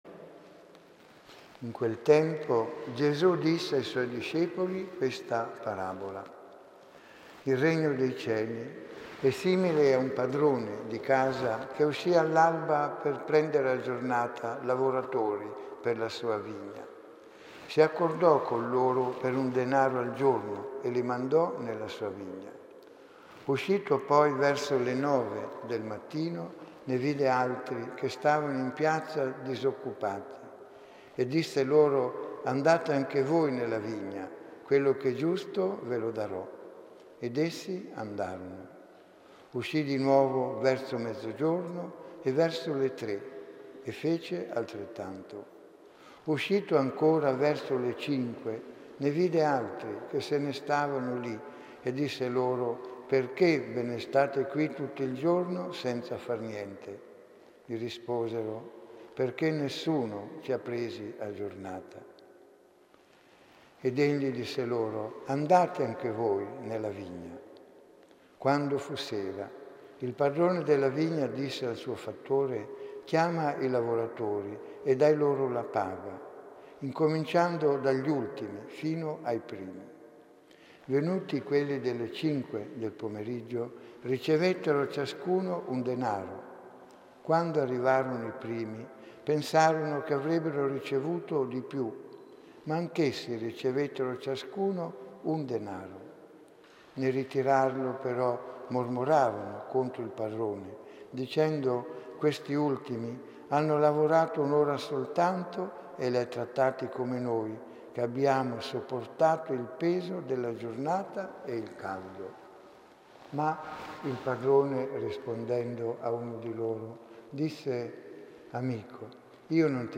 Domenica 15.10.2017 l'omelia di questa Domenica OM171015D [21.873 Kb] Domenica 8 ott. 2017 - XXVII T.O: - portare frutti!